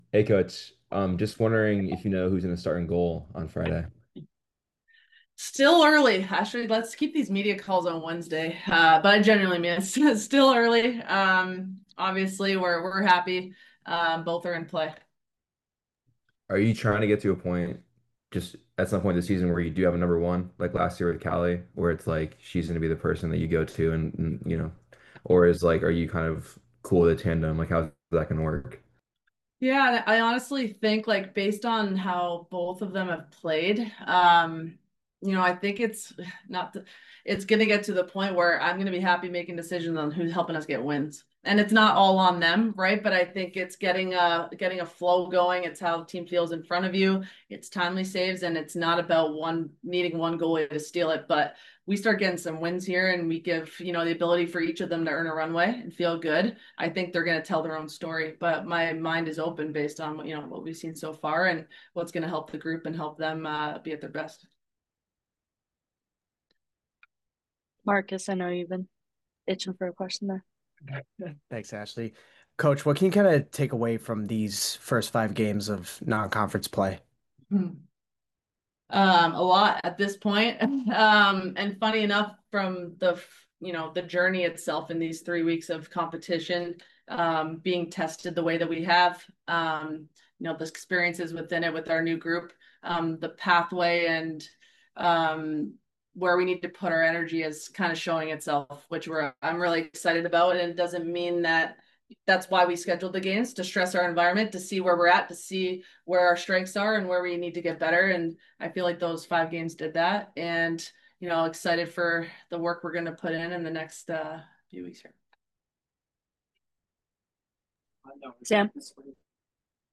Media Call